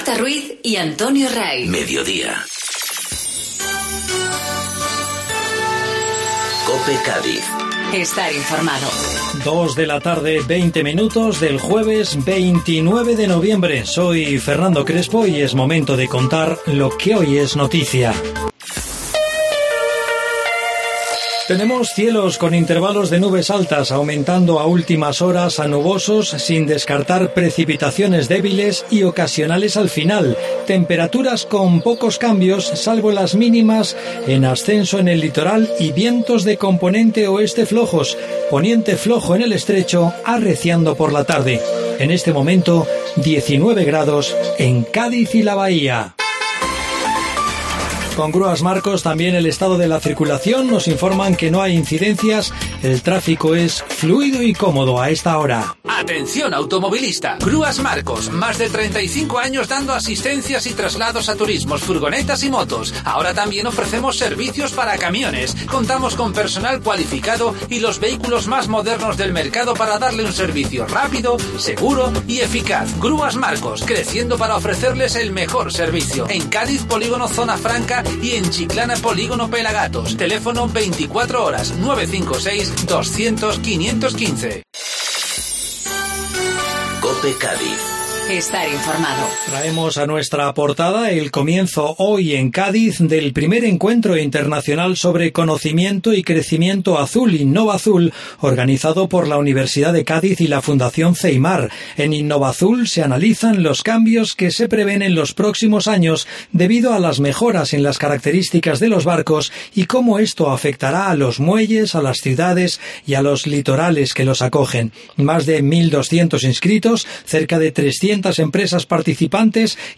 Informativo Mediodía COPE Cádiz